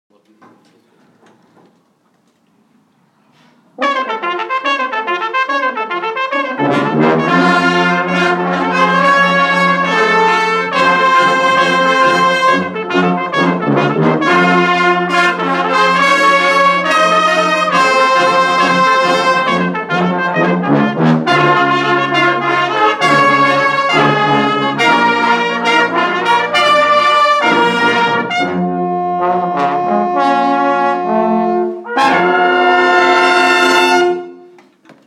Marvel Fanfare from the Brass Ensemble
Live on tour!